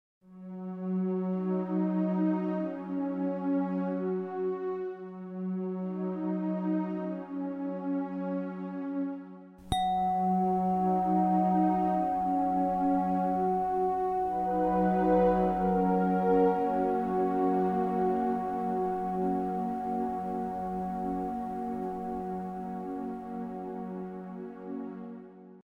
Sie hören jeweils eine begleitende auf die jeweilige Frequenz abgestimmte Tonfolge und die Klangröhre, die exakt in der entsprechenden Frequenz schwingt.
741 Hz (Erwachen, Intuition) - SOlve polluteKomposition "Reinigung und Lösung"
741 Hz I.mp3